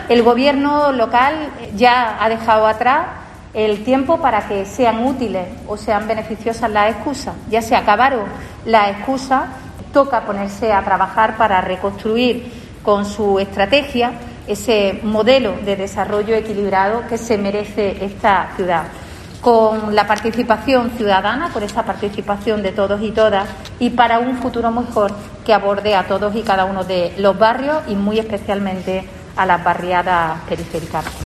En rueda de prensa, Ambrosio ha señalado que el gobierno “desconoce la complejidad y la diversidad de una ciudad que va mucho más allá de lo que se ve desde el balcón de Capitulares”, una Córdoba con un problema de paro endémico, síntomas evidentes y palpables de la dejadez en barrios y barriadas periféricas, que se unen al “desmantelamiento de la red de equipamientos en los barrios, en especial los centros cívicos, la falta de interlocución y los numerosos compromisos incumplidos en los barrios”.